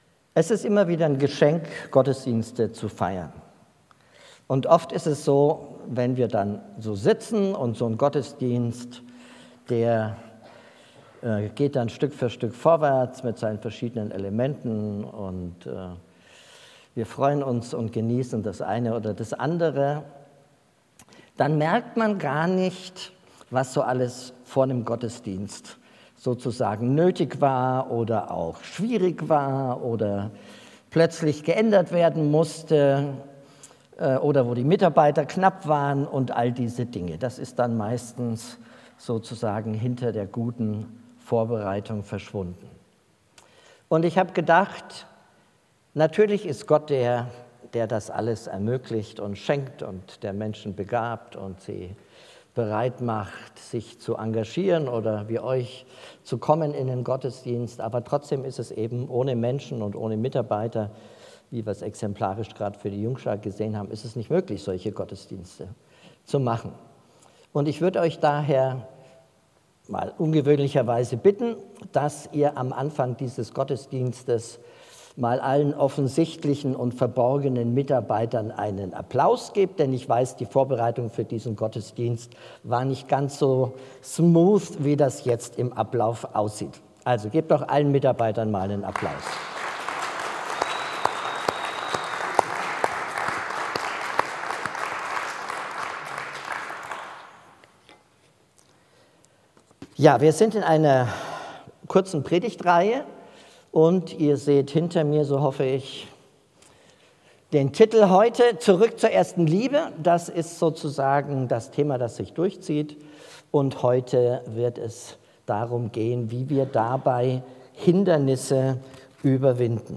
Predigt Vorstellung